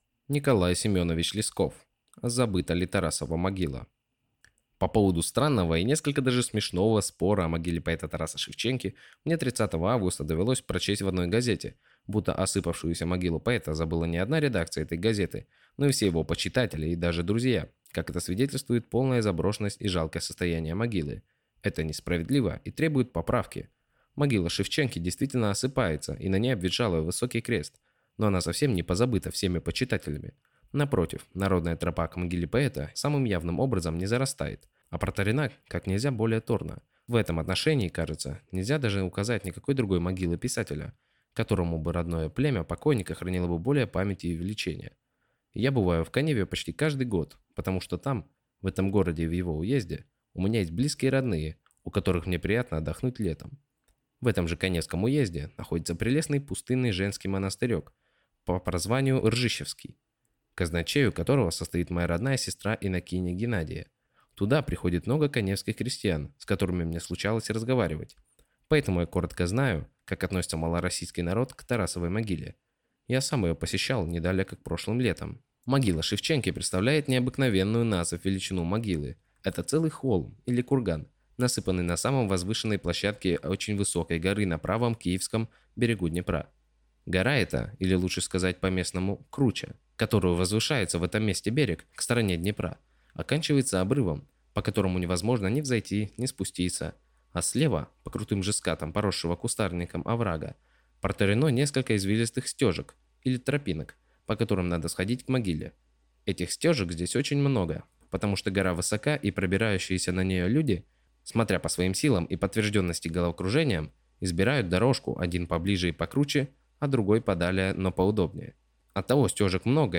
Аудиокнига Забыта ли Тарасова могила?